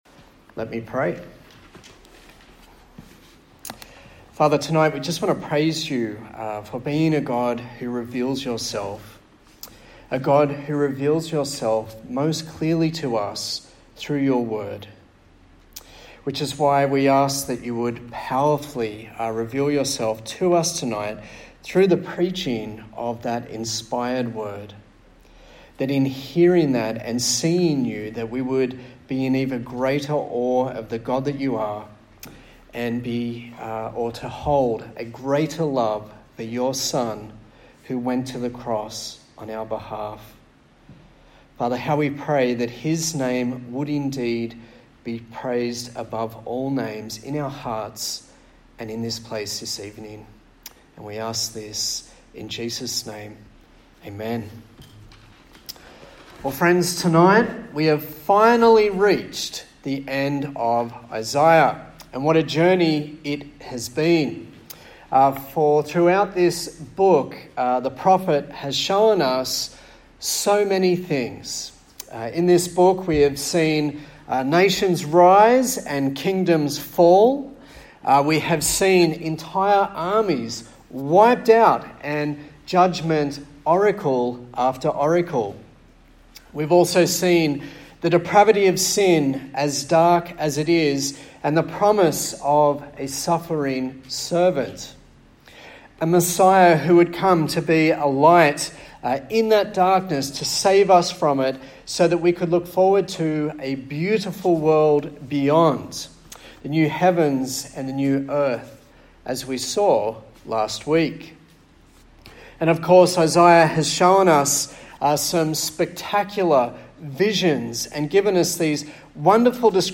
Isaiah Passage: Isaiah 66 Service Type: TPC@5